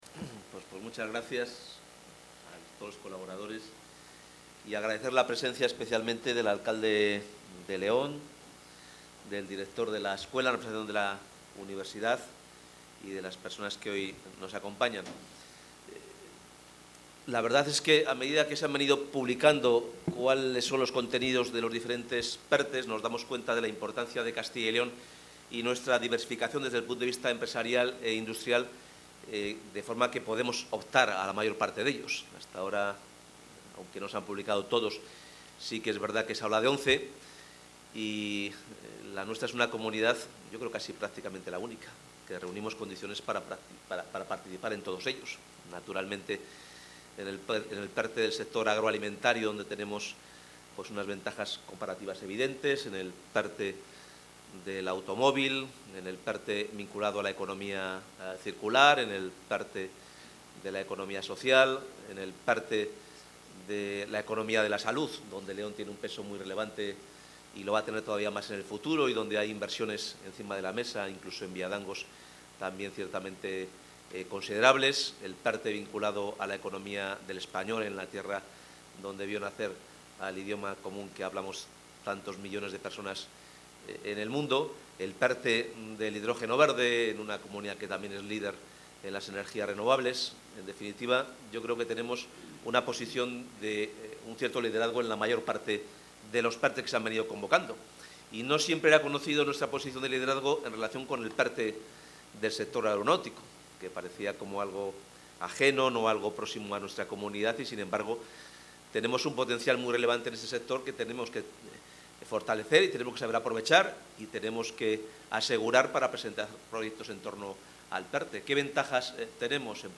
Intervención del consejero.
El consejero de Economía y Hacienda, Carlos Fernández Carriedo, ha inaugurado en el Parque Tecnológico de León la jornada ‘PERTE Aeroespacial. Oportunidades para el ecosistema de Castilla y León’, en la que se ha presentado a las empresas del sector aeroespacial de la Comunidad el ‘Proyecto Estratégico para la Recuperación y Transformación Económica (PERTE)’, que prevé movilizar 4.500 millones de euros del Plan de Recuperación, Transformación y Resiliencia de la Unión Europea.